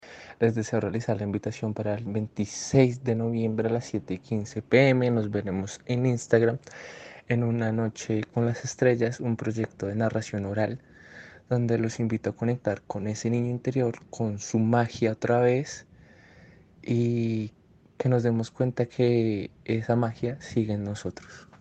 Narracion.mp3